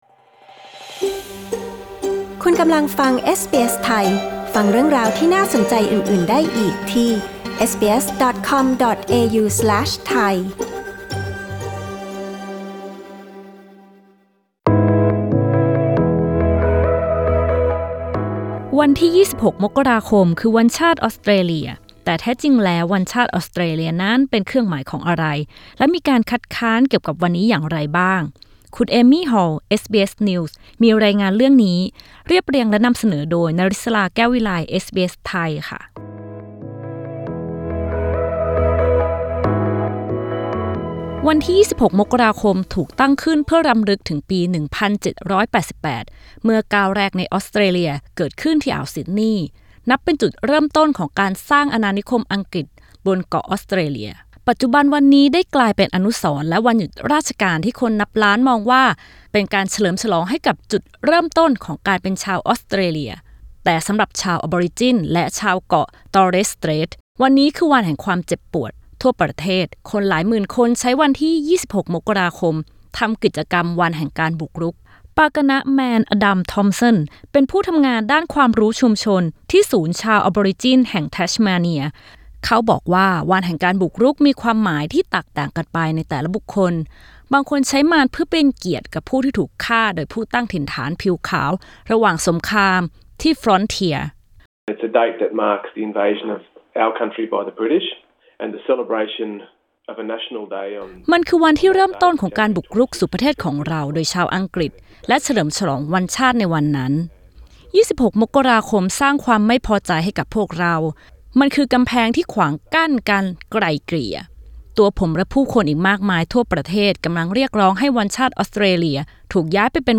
กดปุ่ม 🔊 ที่ภาพด้านบนเพื่อฟังสัมภาษณ์เรื่องนี้